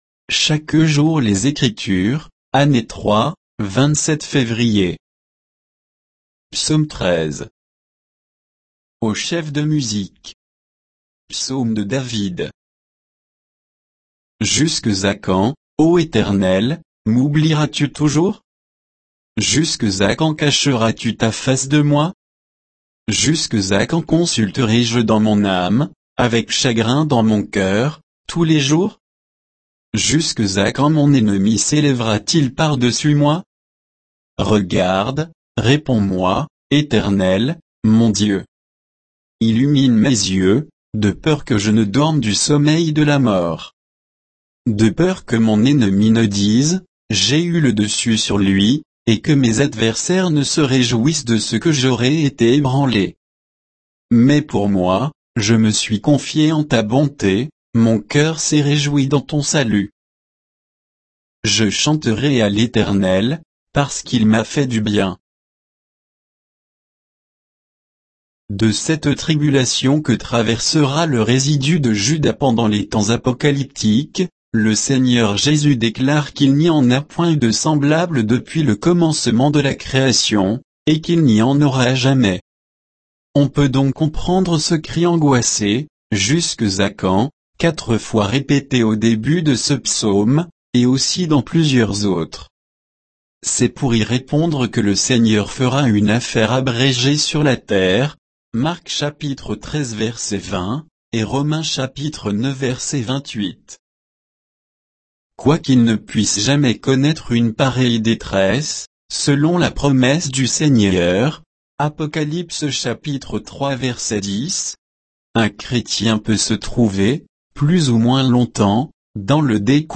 Méditation quoditienne de Chaque jour les Écritures sur Psaume 13